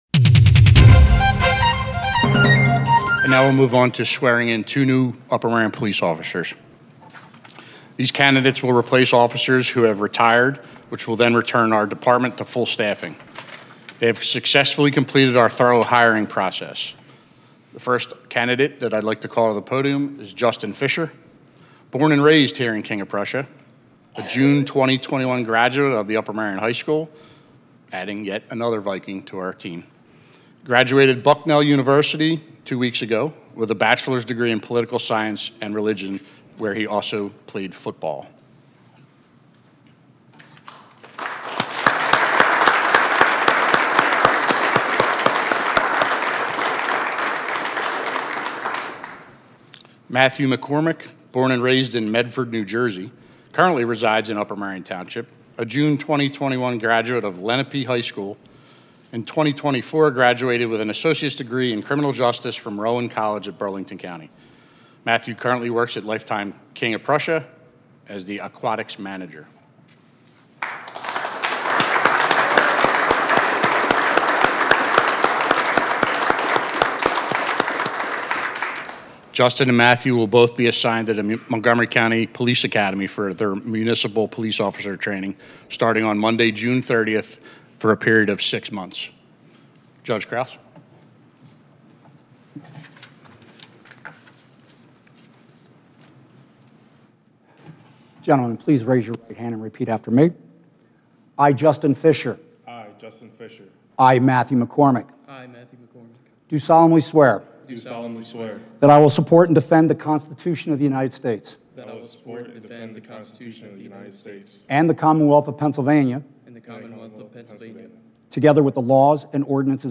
Swearing-In of New Police Officers by Judge Patrick Krouse:
Swearing-In of New EMS Employees by Judge Patrick Krouse: